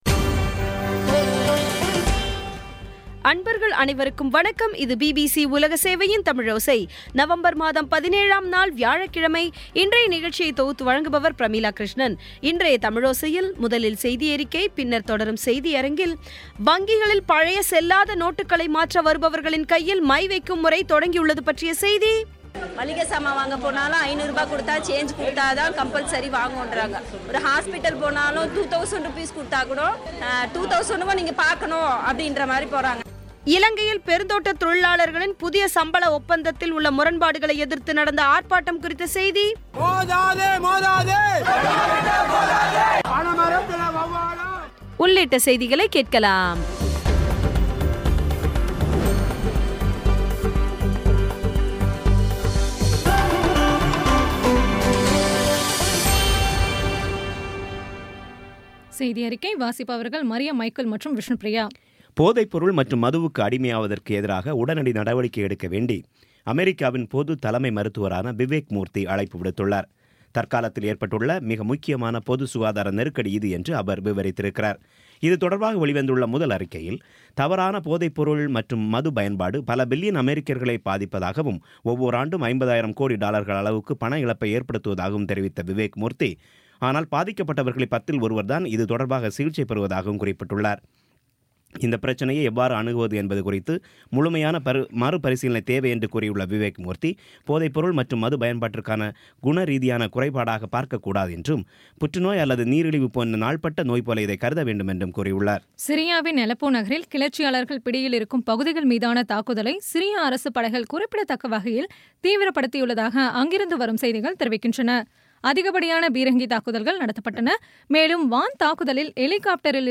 இன்றைய தமிழோசையில், முதலில் செய்தியறிக்கை, பின்னர் தொடரும் செய்தியரங்கத்தில் 500 மற்றும் 1,000 ரூபாய் நோட்டுகள் செல்லாததை அடுத்து மை வைக்கும் நடைமுறை அமலாகியுள்ளது குறித்த செய்தி இலங்கையில் பெருந்தோட்டத் தொழிலாளர்களின் புதிய சம்பள ஒப்பந்தத்தில் உள்ள முரண்பாடுகளை எதிர்த்து நடந்த ஆர்ப்பாட்டம் குறித்த செய்தி உள்ளிட்ட செய்திகளை கேட்கலாம்